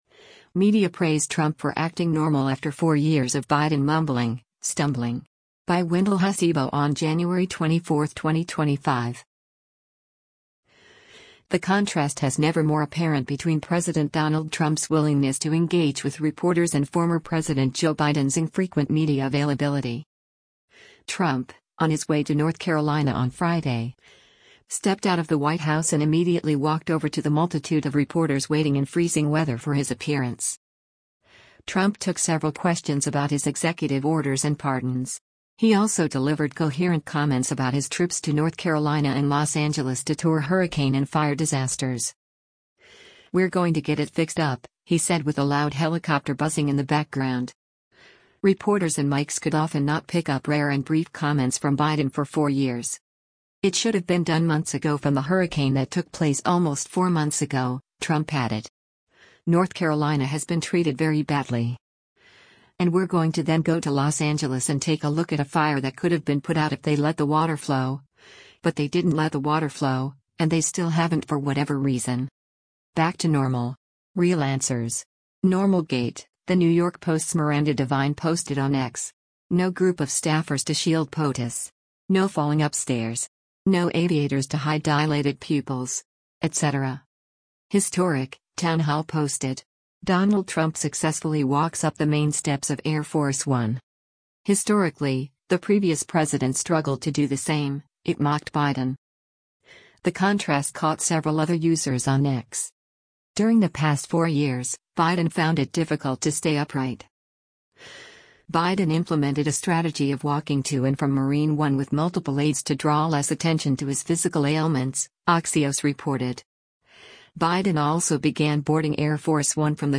Trump, on his way to North Carolina on Friday, stepped out of the White House and immediately walked over to the multitude of reporters waiting in freezing weather for his appearance.
“We’re going to get it fixed up,” he said with a loud helicopter buzzing in the background.